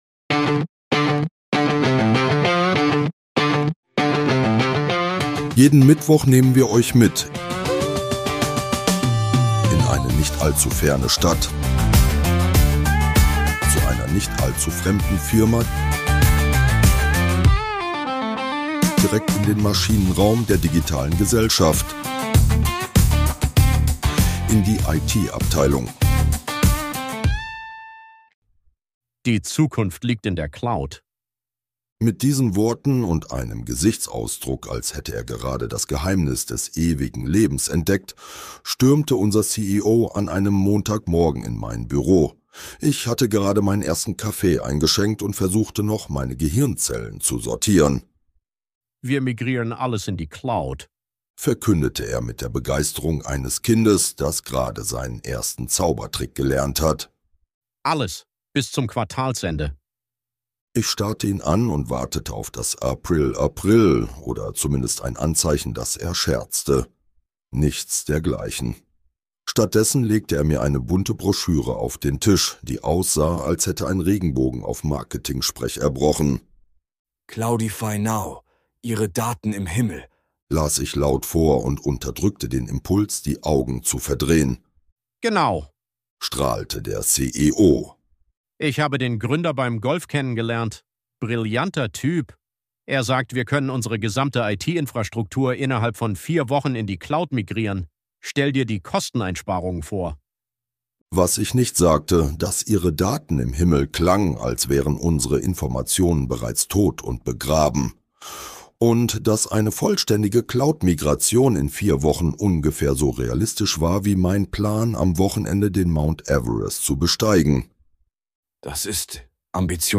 Ein realitätsnahes IT-Desaster zwischen „Agilität“ und „April, April“ – erzählt mit trockenem Humor, technischer Tiefe und der Erkenntnis, dass die Cloud vor allem eines ist: jemand anderes Computer. Dieser Podcast ist Comedy.